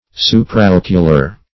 \Su`pra*oc"u*lar\